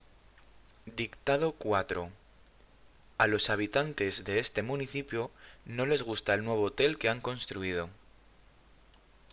Dictado 4